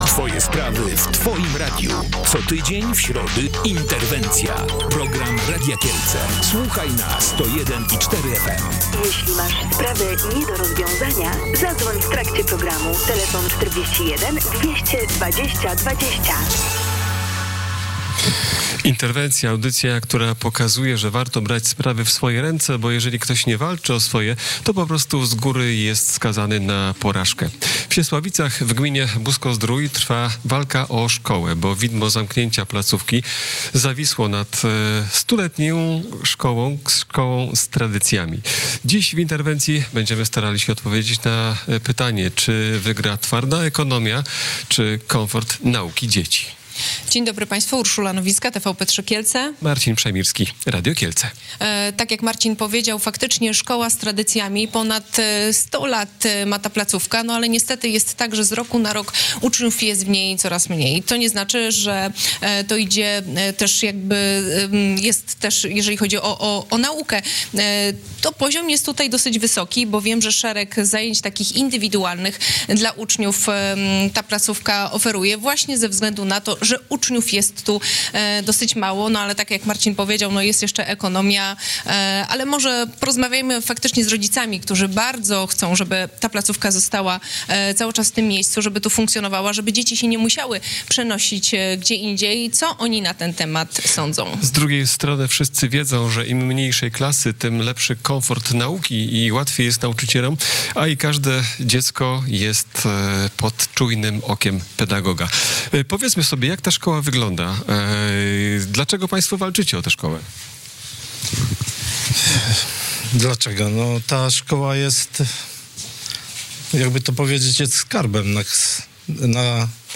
Decyzja buskich radnych o zamiarze likwidacji szkoły wywołała sprzeciw rodziców i uczniów. O tym, czy uda się uratować placówkę przed zamknięciem rozmawialiśmy w środę (21 stycznia) w programie Interwencja.